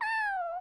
Звуки мяуканья котенка
мяу мяу